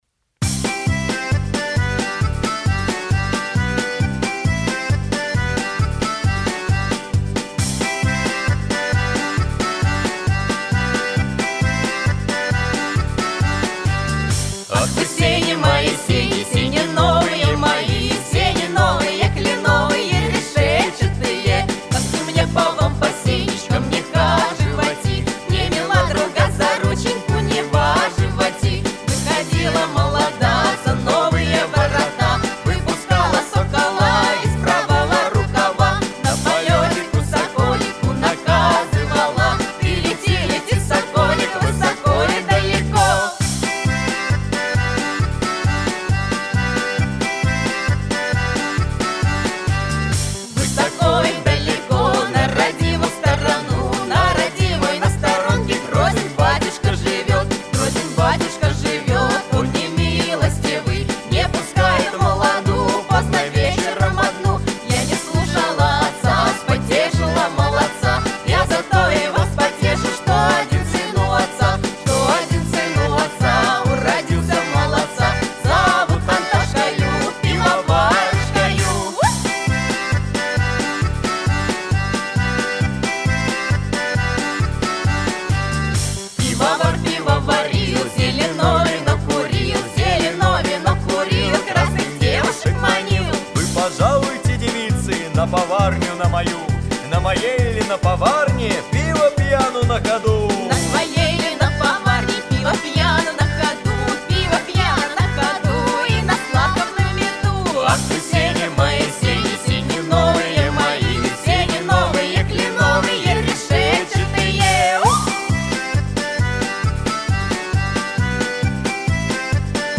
Поп-фолк